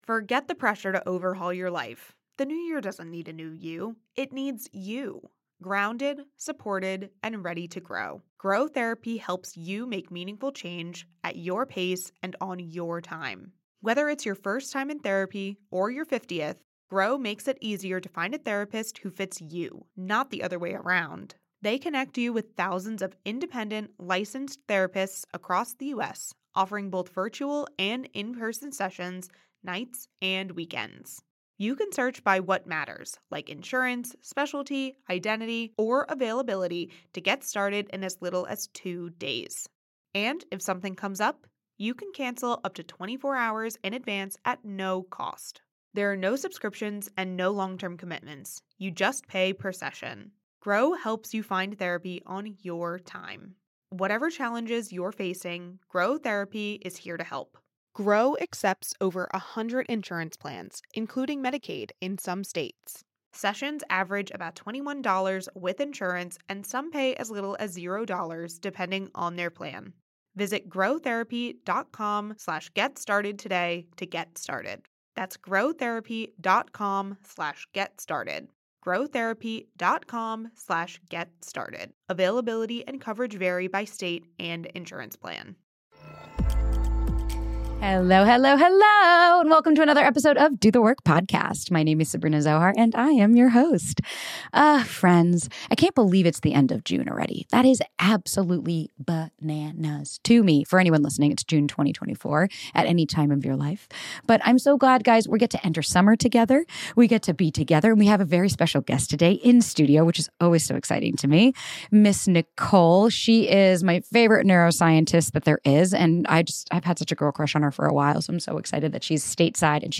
is in the studio